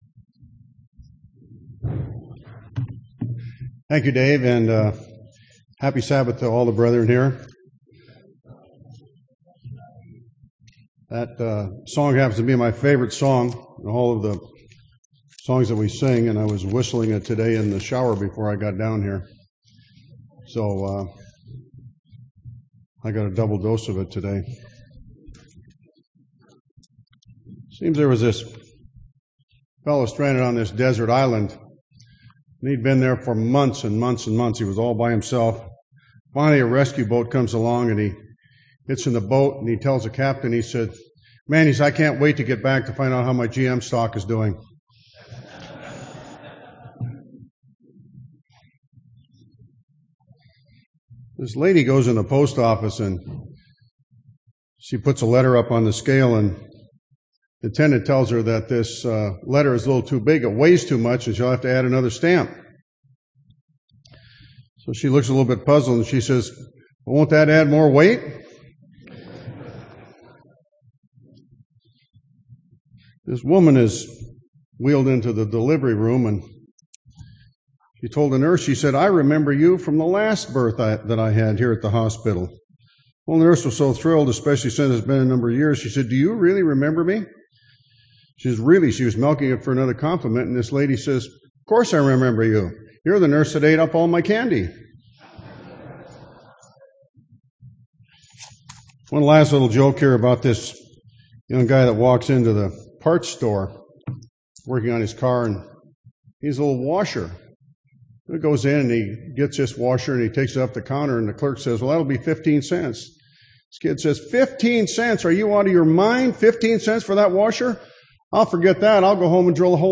UCG Sermon